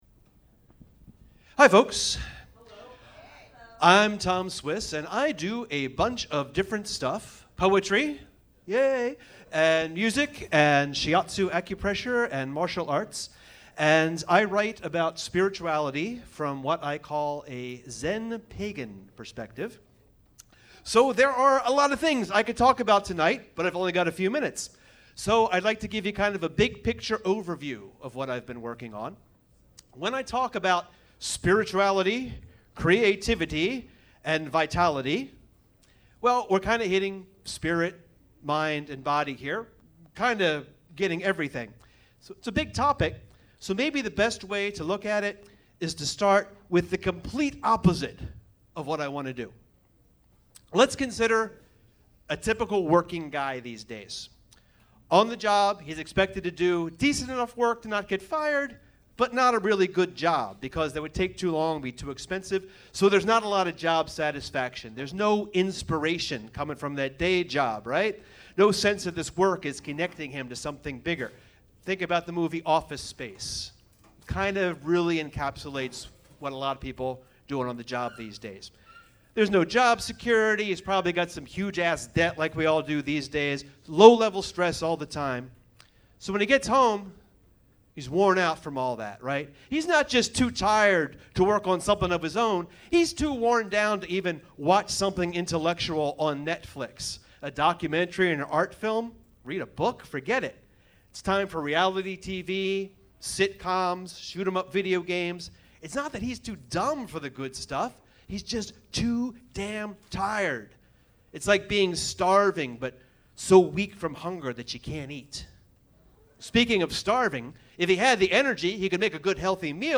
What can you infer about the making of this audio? On October 21st I gave a brief talk at a “meet and greet” event for the Firehouse Arts & Music space opening soon in Baltimore. The MP3 is nicer, it’s right from the mixing board.)